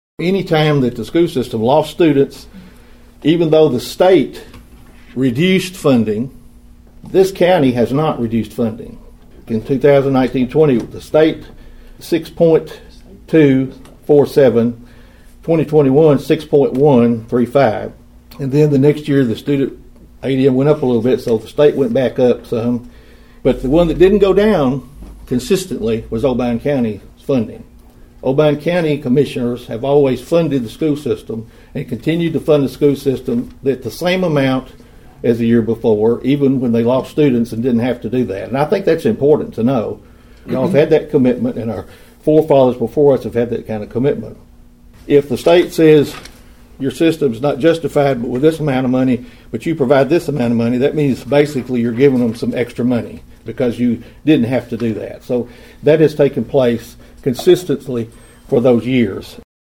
During his nearly 17-minute address to the Budget Committee last week, Mayor Carr said County Commissioners have exceeded their maintenance of effort for the schools.(AUDIO)